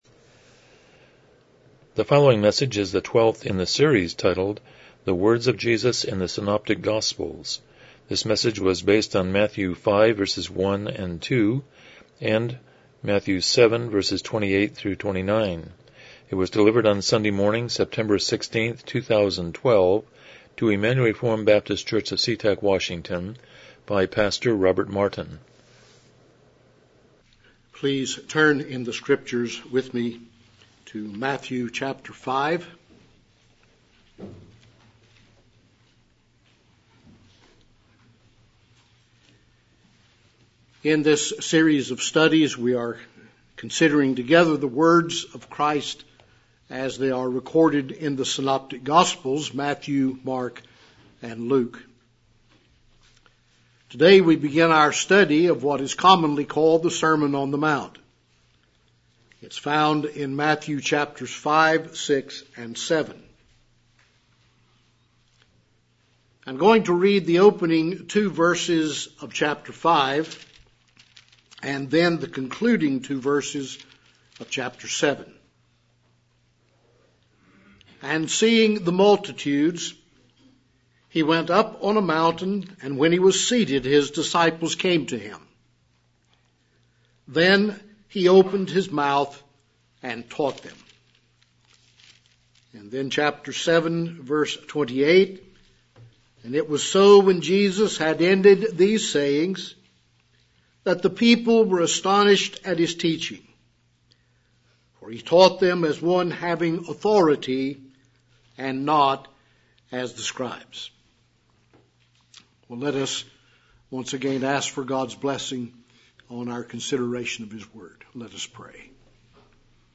Passage: Matthew 5:1-2, Matthew 7:28-29 Service Type: Morning Worship